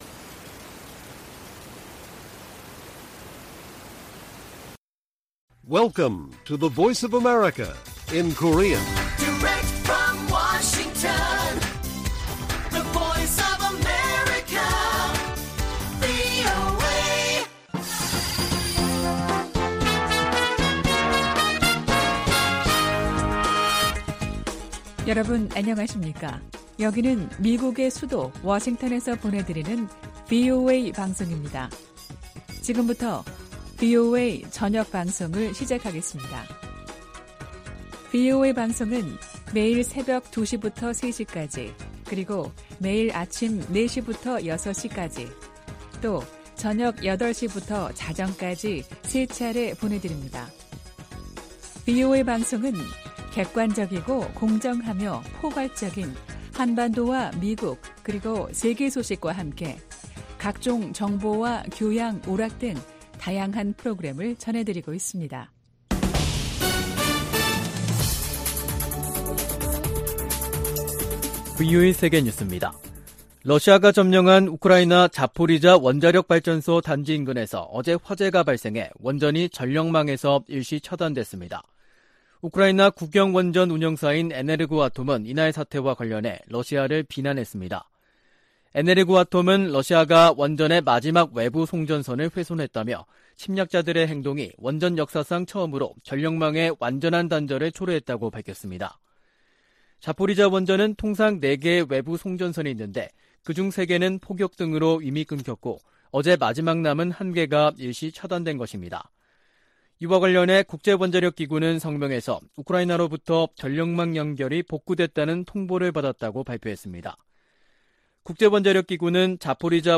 VOA 한국어 간판 뉴스 프로그램 '뉴스 투데이', 2022년 8월 26일 1부 방송입니다. 미 국무부 차관보와 한국 외교부 차관보가 서울에서 회담하고 북한의 도발 중단과 대화 복귀를 위한 공조를 강화하기로 했습니다. 미 국무부는 반복되는 러시아와 중국 폭격기의 한국 방공식별구역 진입을 역내 안보에 대한 도전으로 규정했습니다. 미국과 한국 정부가 중국 내 탈북 난민 상황을 거듭 우려하며 중국 정부에 난민 보호에 관한 국제의무 이행을 촉구했습니다.